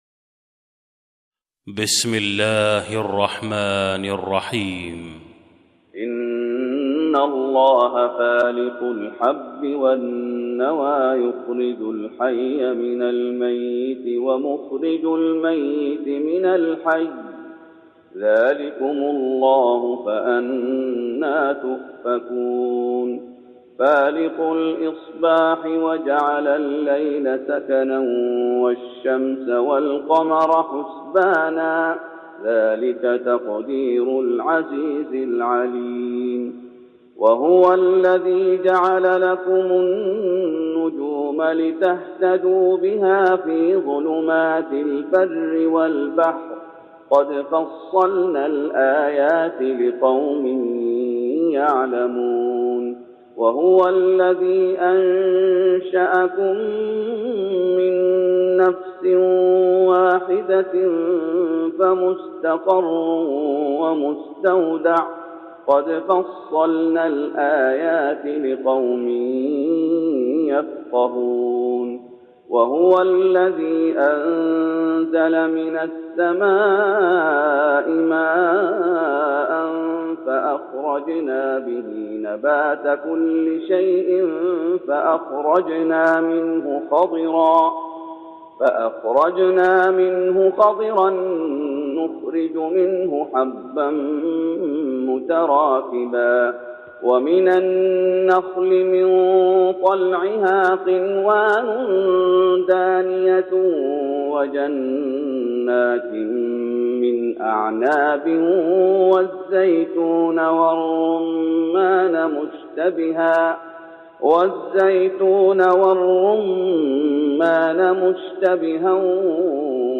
تهجد رمضان 1412هـ من سورة الأنعام (95-110) Tahajjud Ramadan 1412H from Surah Al-An’aam > تراويح الشيخ محمد أيوب بالنبوي 1412 🕌 > التراويح - تلاوات الحرمين